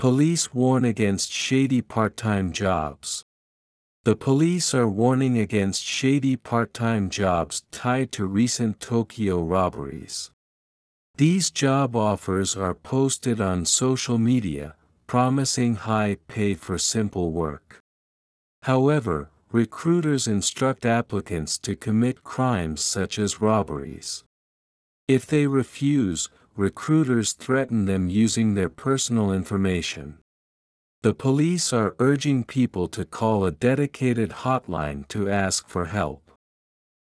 【スロースピード】